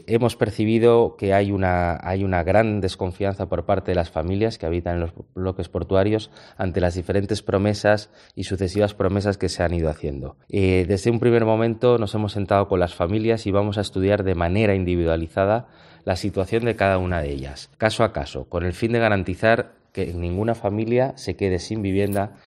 Juan Giner, concejal de urbanismo: "El ayuntamiento busca ofrecer una solución personalizada a cada familia"